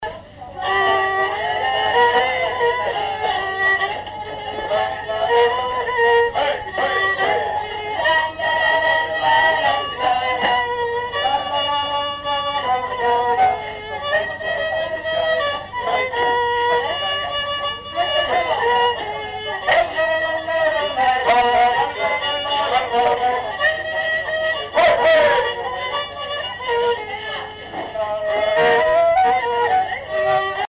danse
Répertoire d'un bal folk par de jeunes musiciens locaux
Pièce musicale inédite